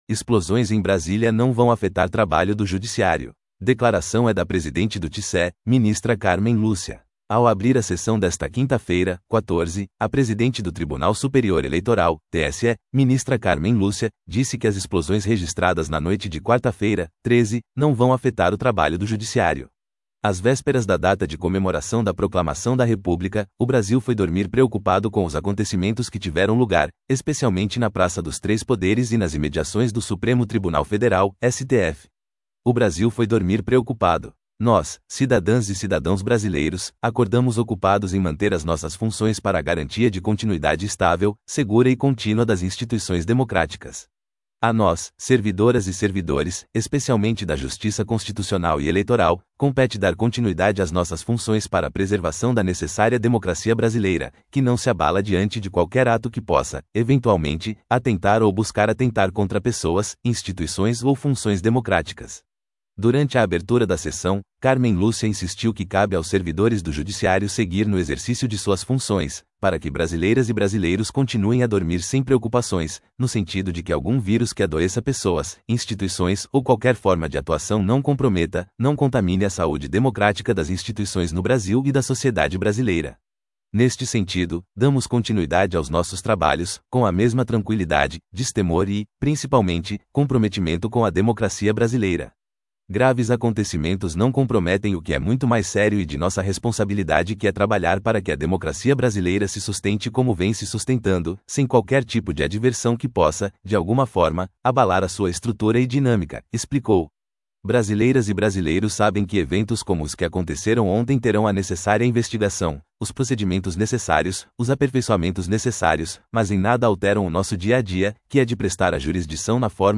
Declaração é da presidente do TSE, ministra Cármen Lúcia
Ao abrir a sessão desta quinta-feira (14), a presidente do Tribunal Superior Eleitoral (TSE), ministra Cármen Lúcia, disse que as explosões registradas na noite de quarta-feira (13) não vão afetar o trabalho do Judiciário.